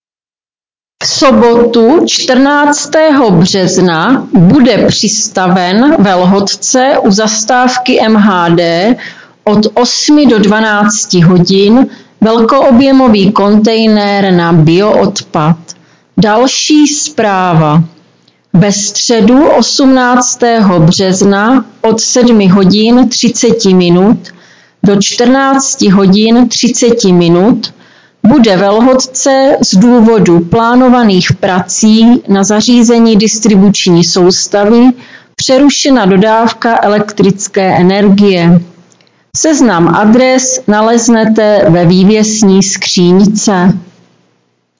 Hlášení místního rozhlasu
Hlášení ze dne 13.3.2026